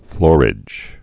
(flôrĭj)